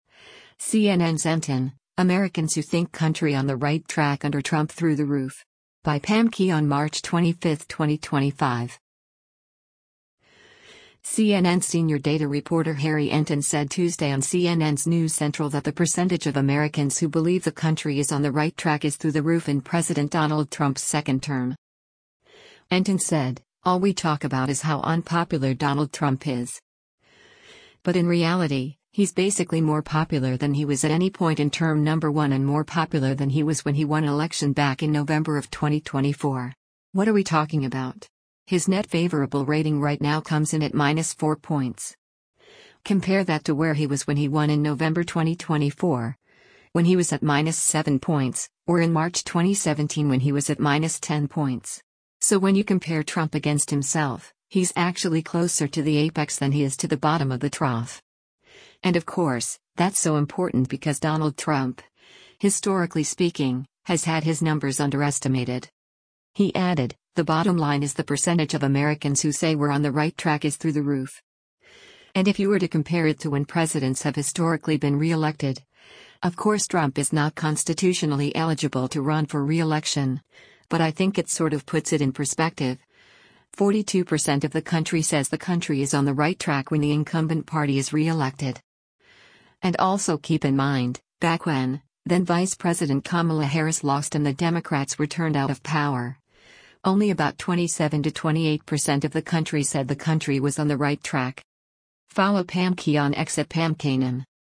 CNN senior data reporter Harry Enten said Tuesday on CNN’s “News Central” that the percentage of Americans who believe the country is on the right track is “through the roof” in President Donald Trump’s second term.